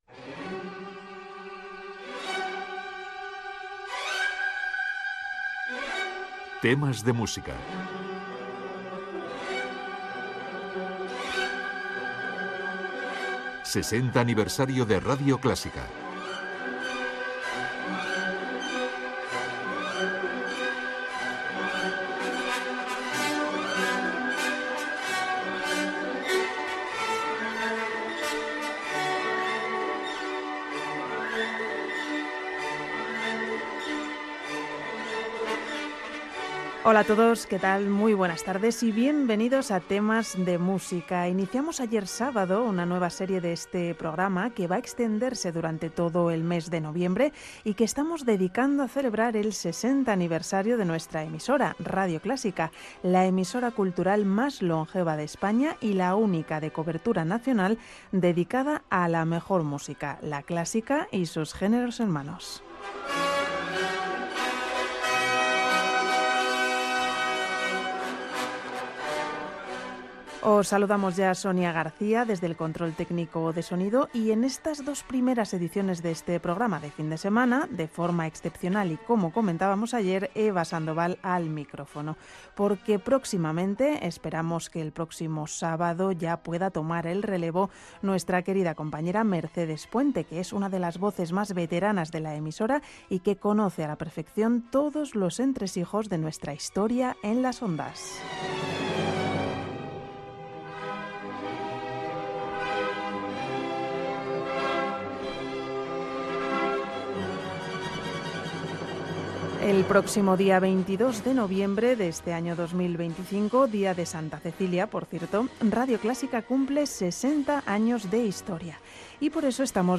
Careta del programa, espai dedicat als 60 anys de Radio Clásica, la dècada de 1975 a 1985